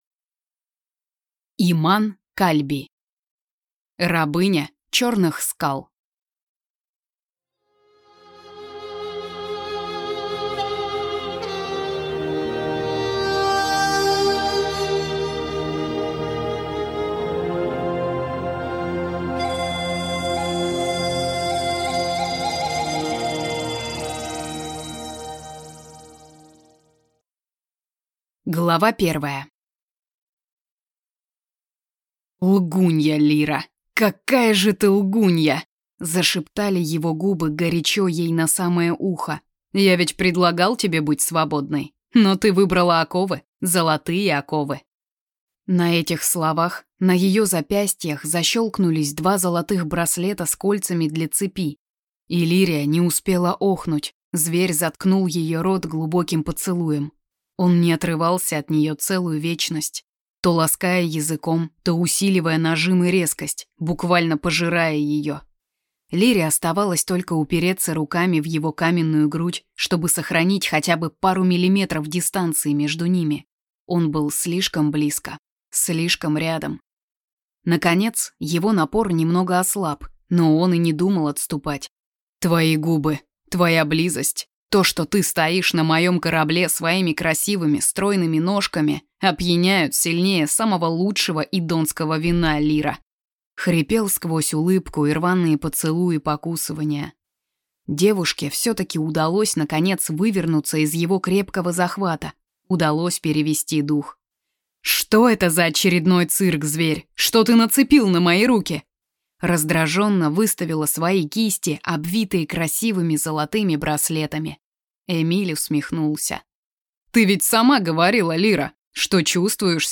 Аудиокнига Рабыня черных скал | Библиотека аудиокниг